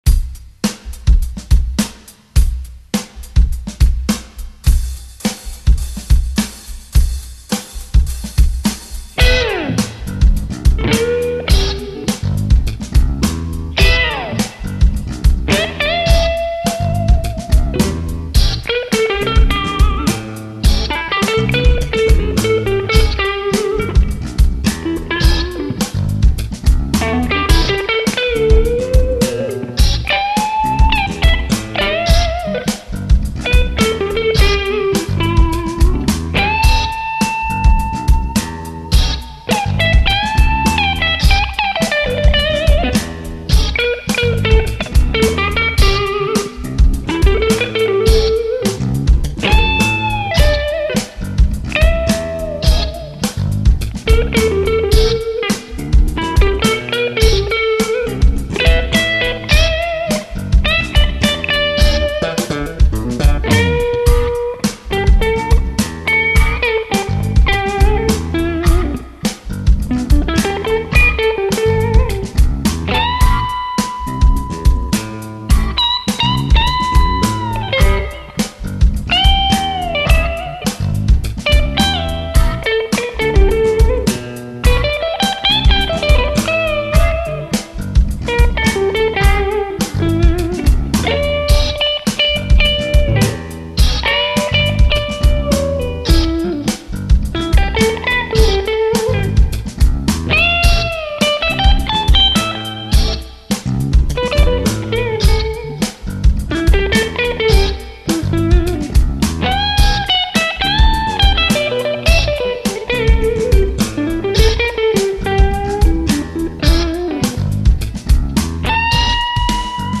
bluesy stabs - mp3.
Das ist nun wieder die Orville mit dem Womanizer.